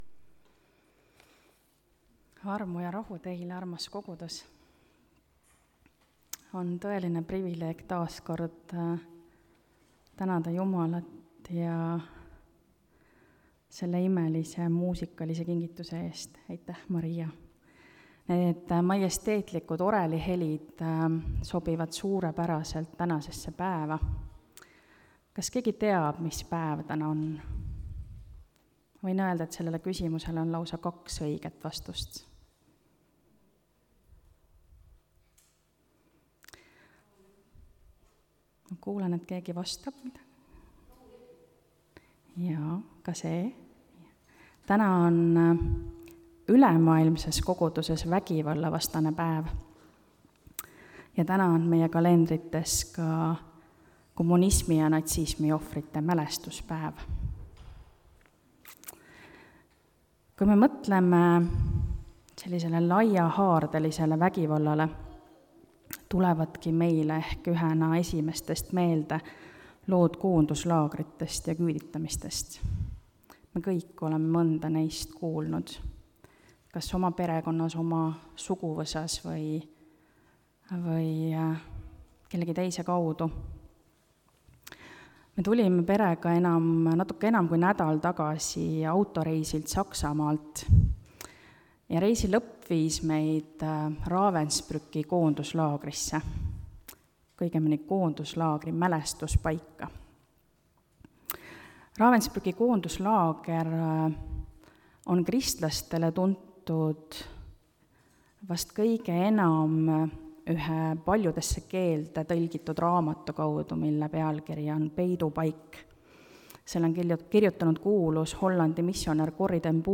(Tallinnas)
Jutlused